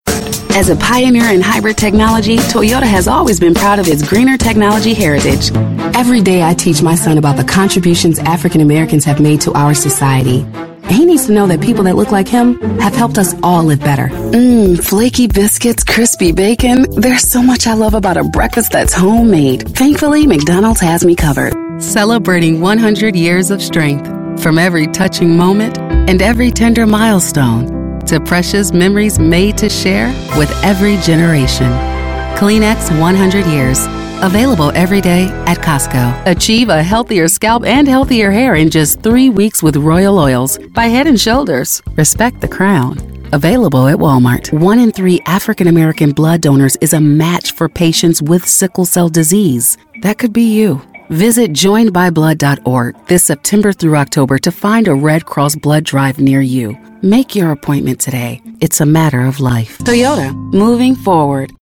Voiceover : Political : Women